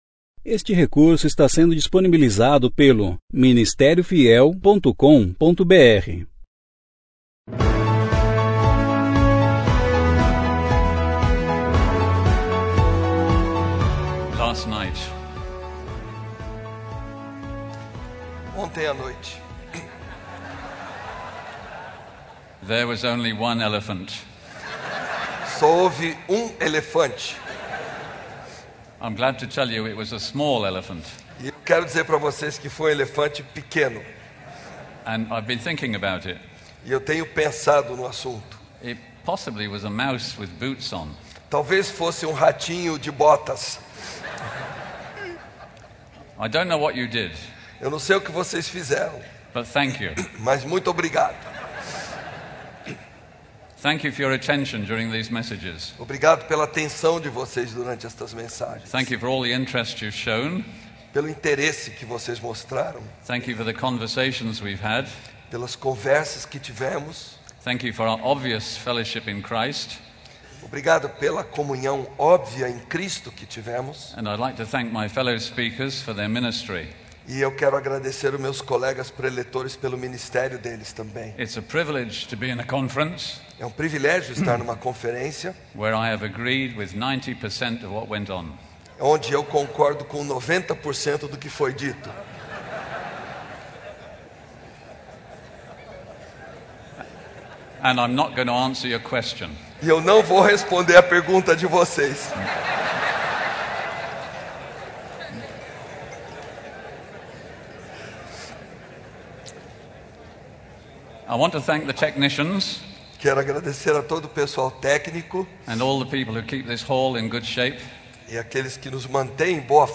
27ª Conferência Fiel para Pastores e Líderes – Brasil - Ministério Fiel